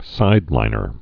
(sīdlīnər)